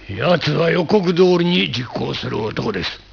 breve sonoro